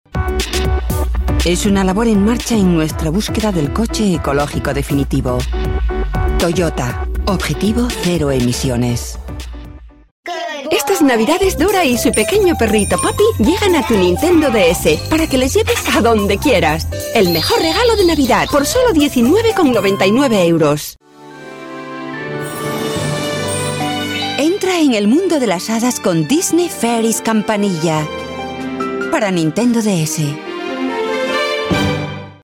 Sprecherin spanisch. Werbesprecherin. Sprecherin für Sprachkurse.
kastilisch
Sprechprobe: Werbung (Muttersprache):
Spanish female voice over talent.